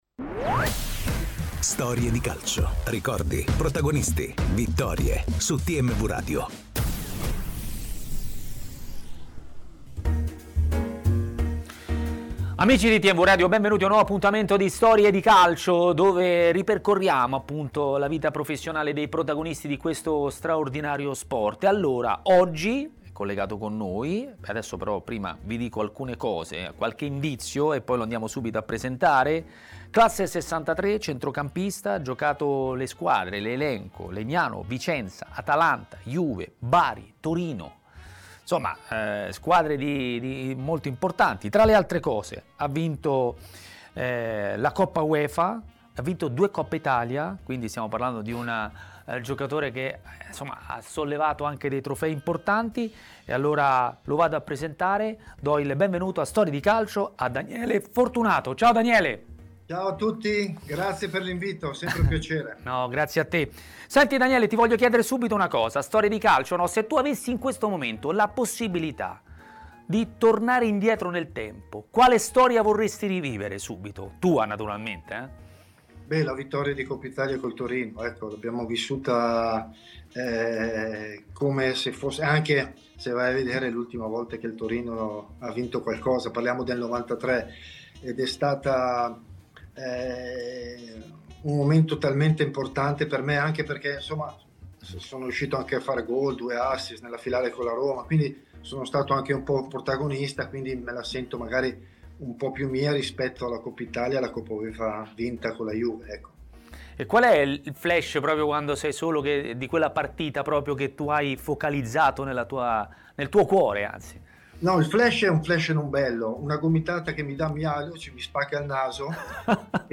Così Daniele Fortunato, che si è raccontato ai microfoni di TMW Radio, durante Storie di Calcio.